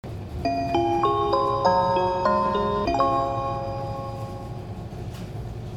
駅発車後用と到着前用のオリジナルがあり、到着前用は京阪のチャイムに似ています。“
nishitetsu-chime2.mp3